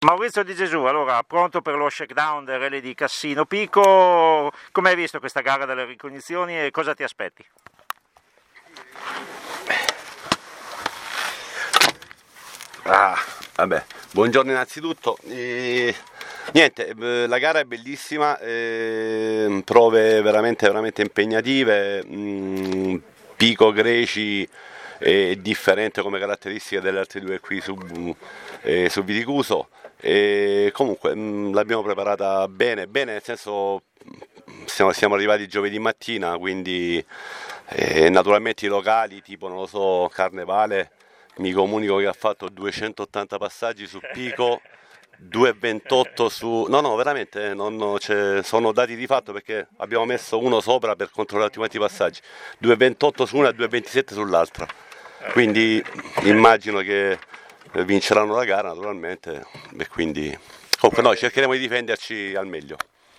Interviste Rally di Cassino / Pico
Interviste pre-gara